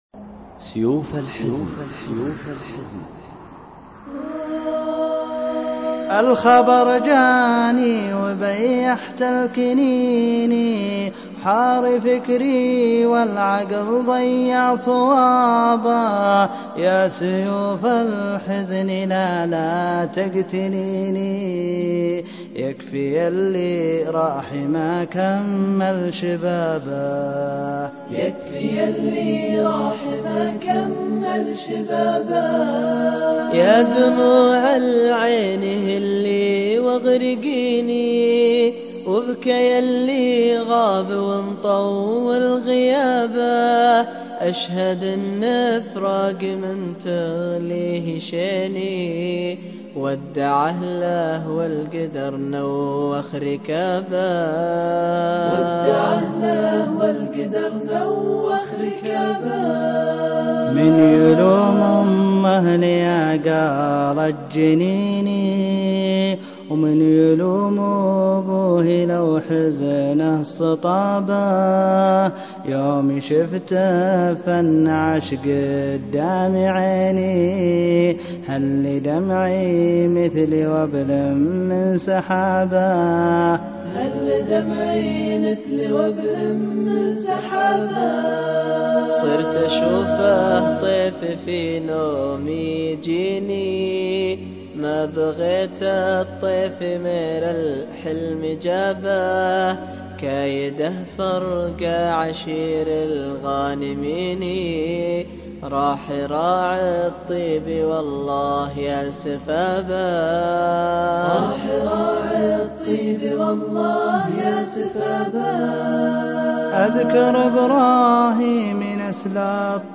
مرثية
الشاعر والمنشد
وكم تقطع القلب على اللحن وعلى الاداء الجميل
بصوت الشجي